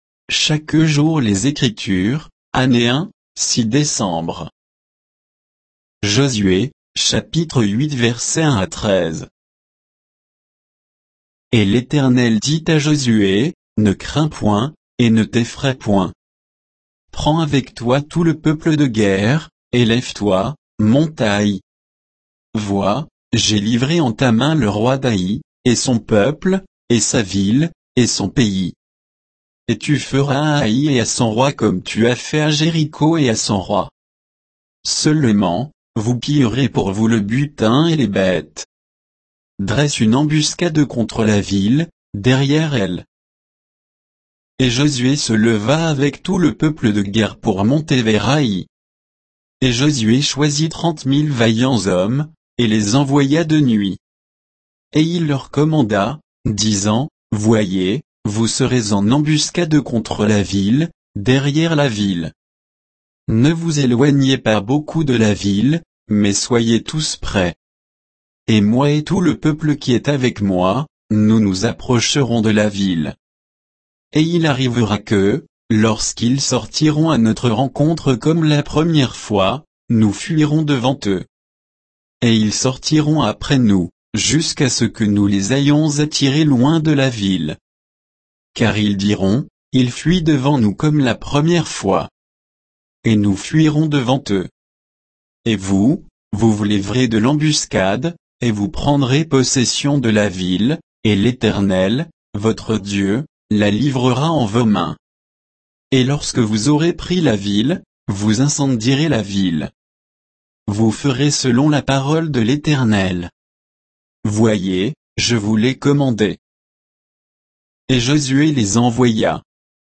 Méditation quoditienne de Chaque jour les Écritures sur Josué 8, 1 à 13